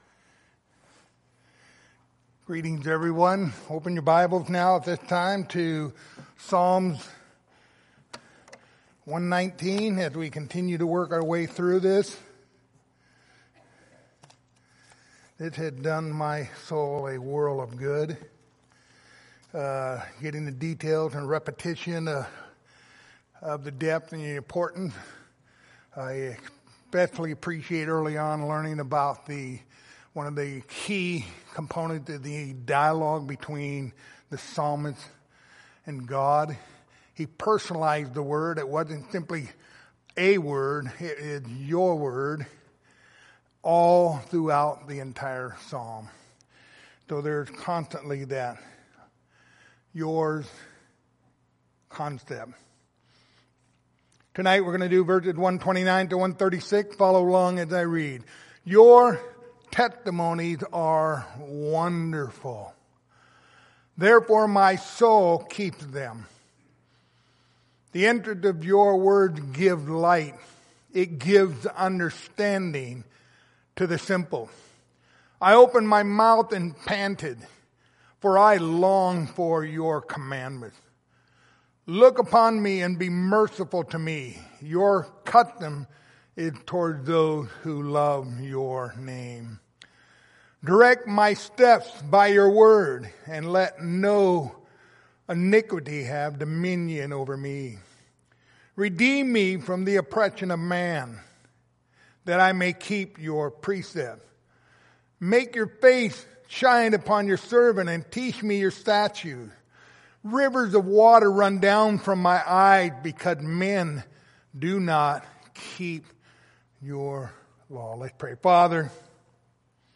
Passage: Psalm 119:129-136 Service Type: Sunday Evening